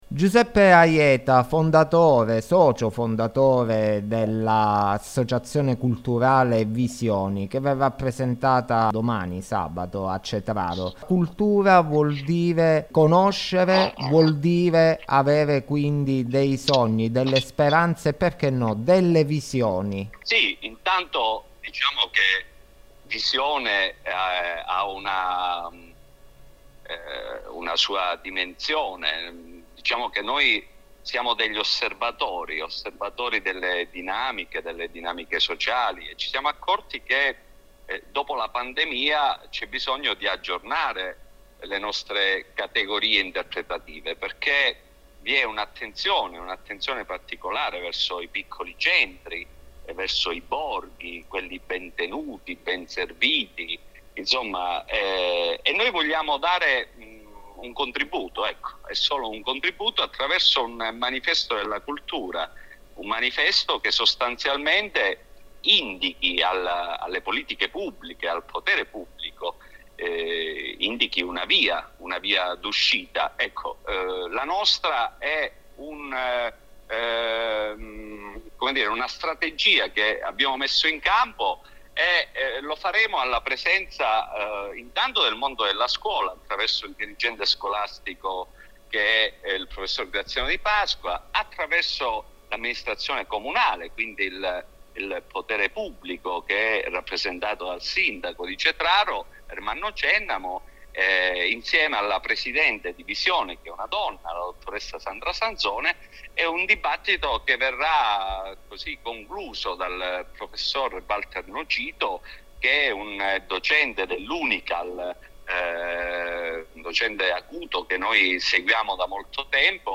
Aieta_Intervista.mp3